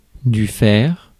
Ääntäminen
Tuntematon aksentti: IPA: /fɛʁ/